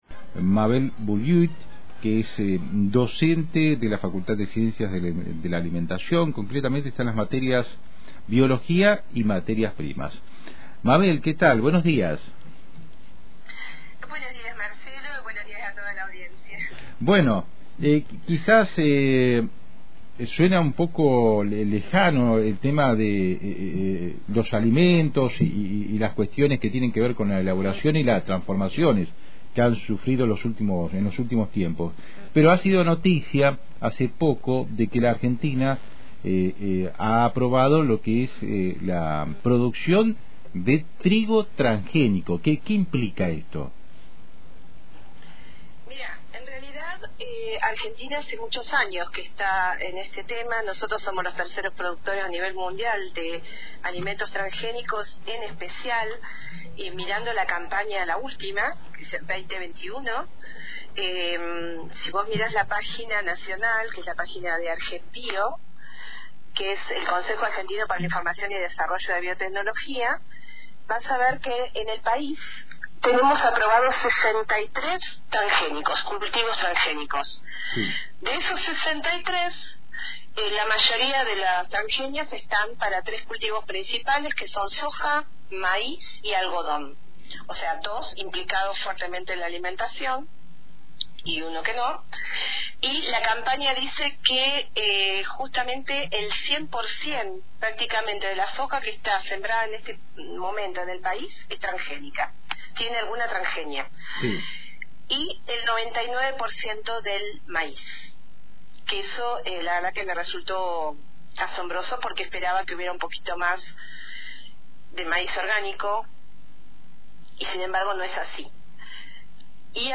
En diálogo con Antena Libre